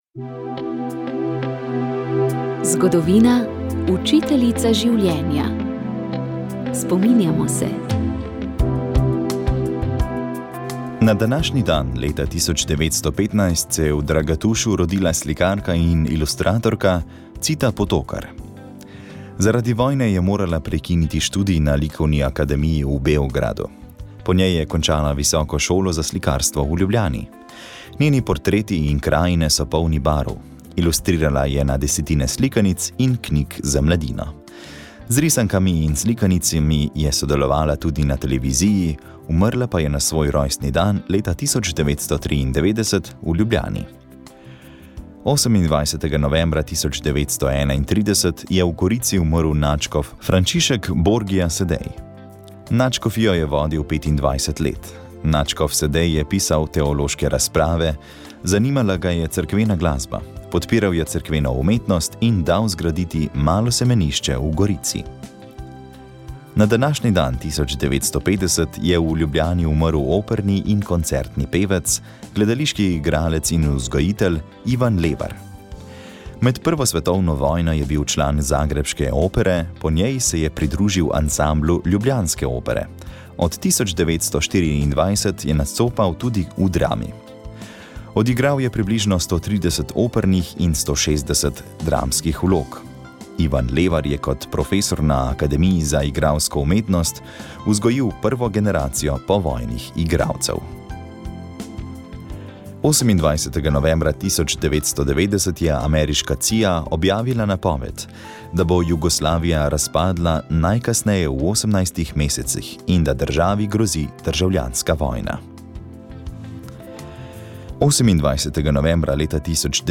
Naša želja je bila odpreti prostor za iskren in spoštljiv pogovor med teisti in ateisti. Zadnja oddaja povzema ključna spoznanja, ki jih je prinesel dialog, besedo pa je imelo tudi občinstvo, ki se je zbralo ob snemanju oddaje.